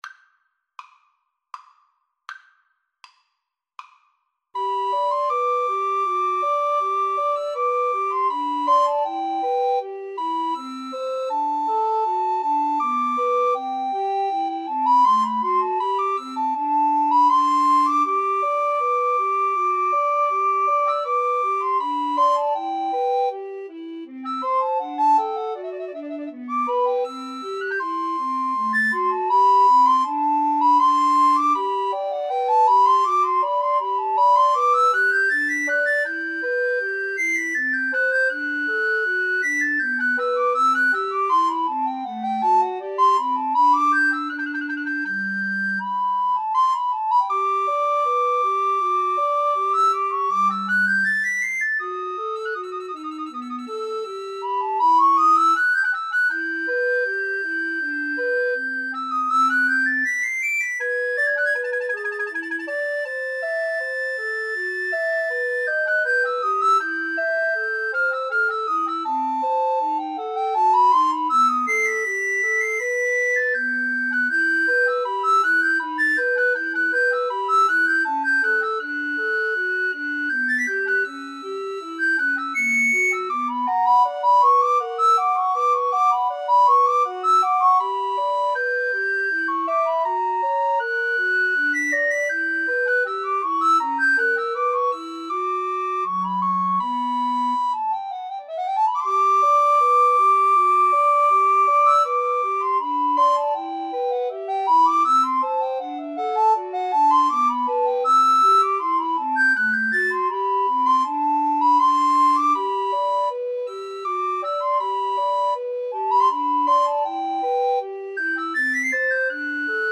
3/4 (View more 3/4 Music)
Andante
Classical (View more Classical Recorder Trio Music)